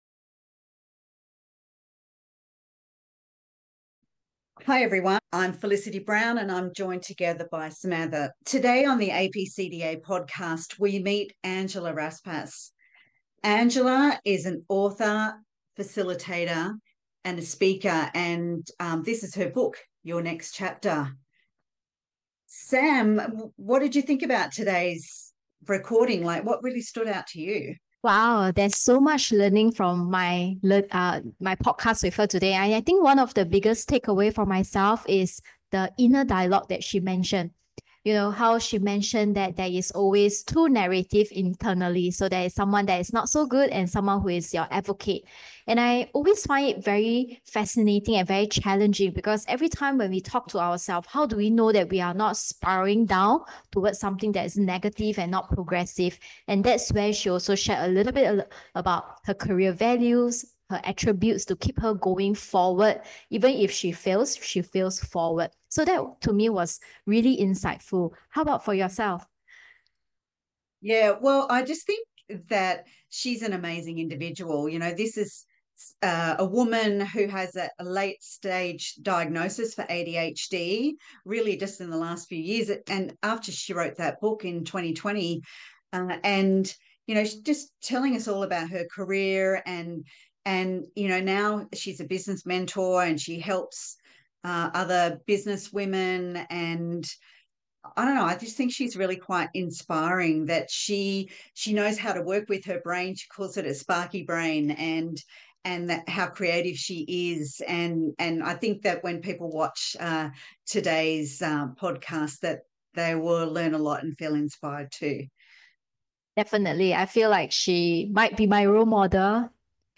APCDA Podcast is a monthly series on career-related discussions. The podcast is in its fifth season in 2025, continuing the theme of ‘Career Journeys’ where the co-hosts interview a range of people with fascinating career journeys to learn about their key career decisions and ...